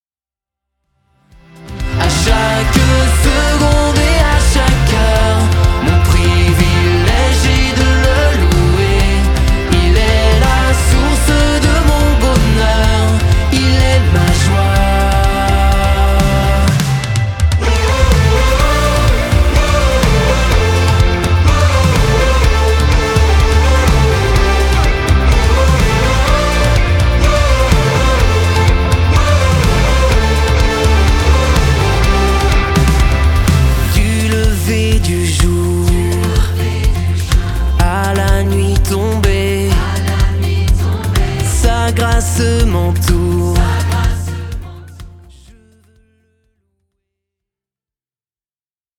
pop louange